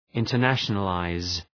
Προφορά
{,ıntər’næʃənə,laız}